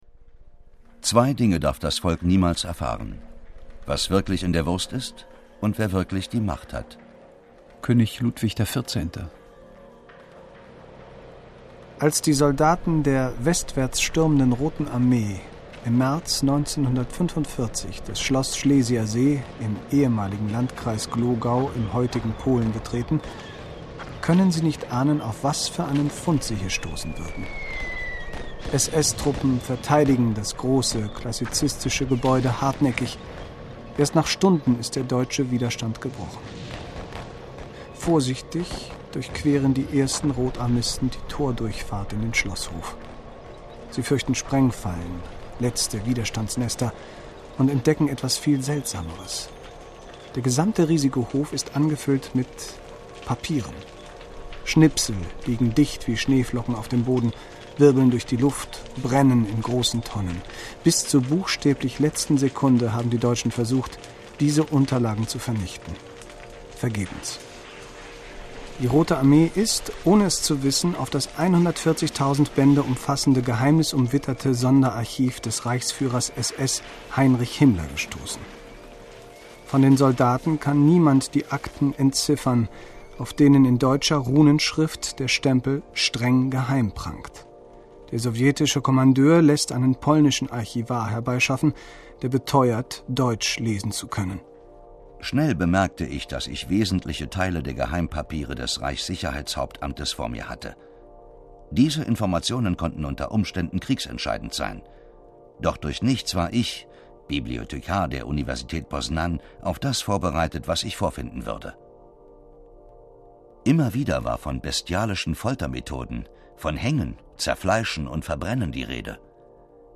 Ein fesselndes Feature zu einer der Schlüsselorganisationen in der Geschichte der Geheimbünde, inszeniert mit Musik, O-Tönen und mehreren Sprechern - diese Hördokumentation bringt Erstaunliches ans Licht ...